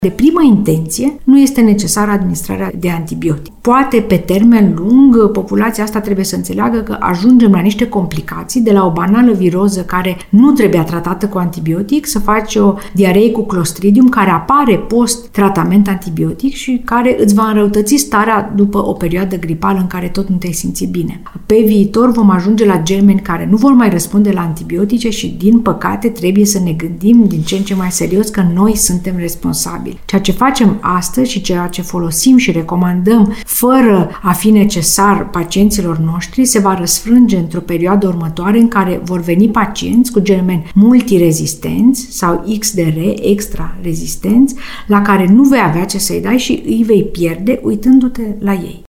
Ea a declarat postului nostru că aceste medicamente nu au efecte la viroze sau la gripă, dar pot determina efecte negative pe termen lung.